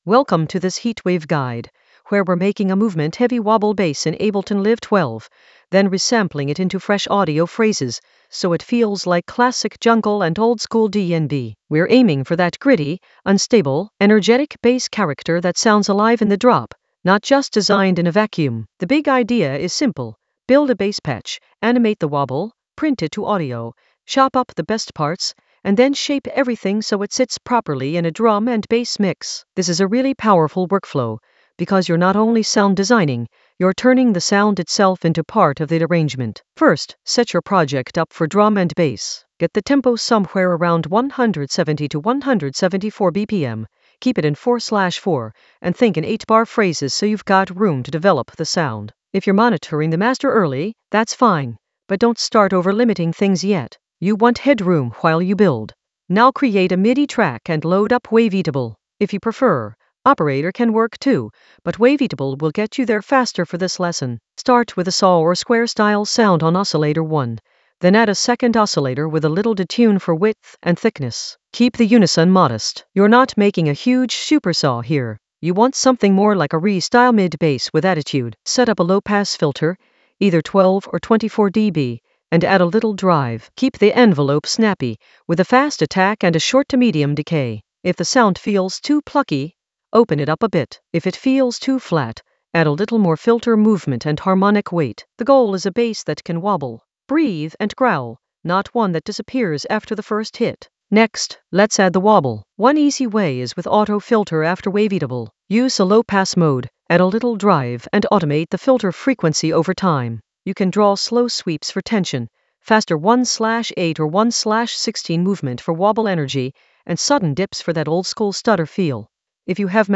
An AI-generated intermediate Ableton lesson focused on Heatwave guide: bass wobble resample in Ableton Live 12 for jungle oldskool DnB vibes in the Mastering area of drum and bass production.
Narrated lesson audio
The voice track includes the tutorial plus extra teacher commentary.